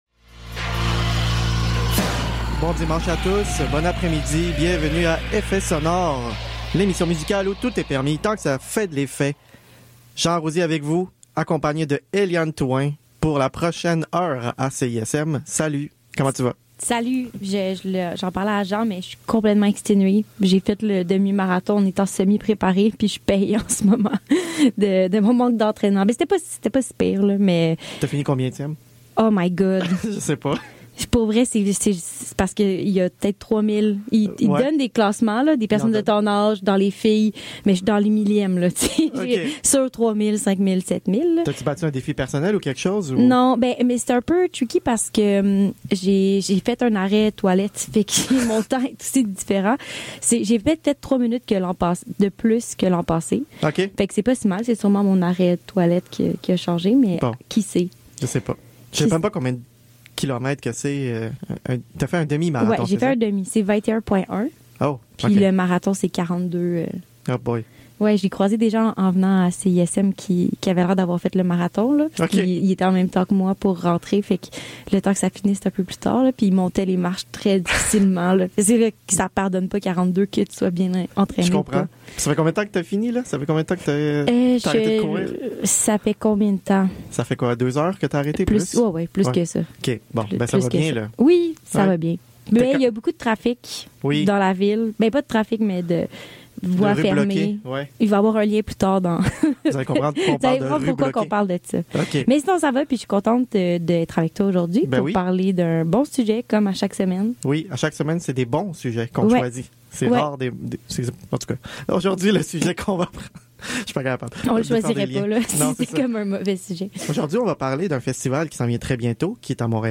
À l’occasion de la 24e édition de POP Montréal, on consacre l\'épisode de cette semaine à ce festival éclectique et indépendant qui fait vibrer le Mile End depuis plus de 20 ans. Au bout du fil avec nous